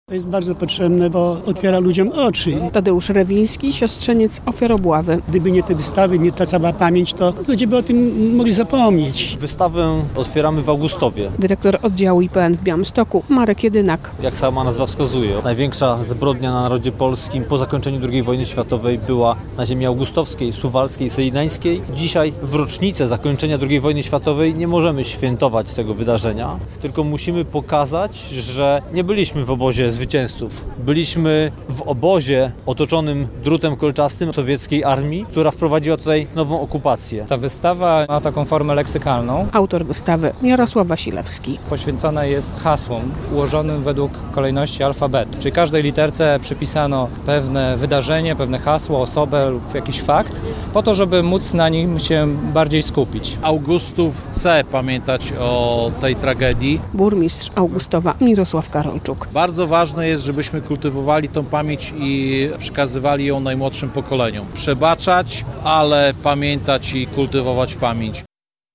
Otwarcie wystawy plenerowej IPN "...I nigdy nie wrócili do domu" w Augustowie - relacja